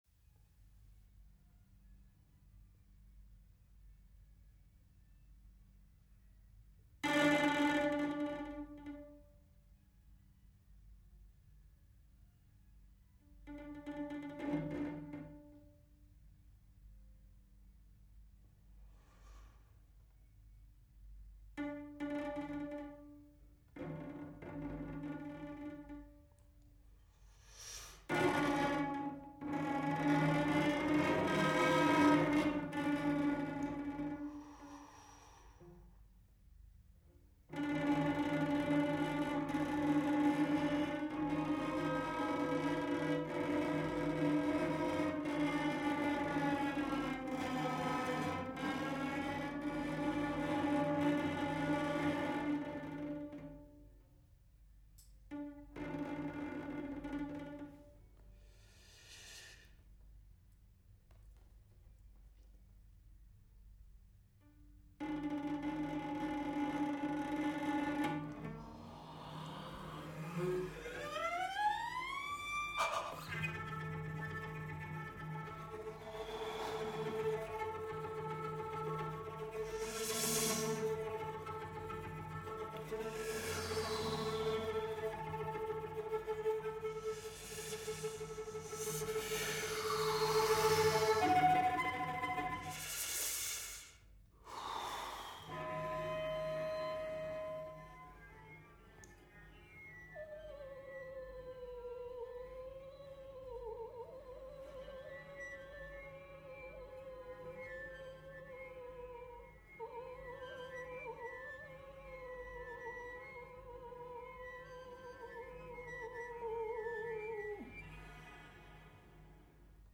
violoncelliste
concertiste et compositeur de musique contemporaine.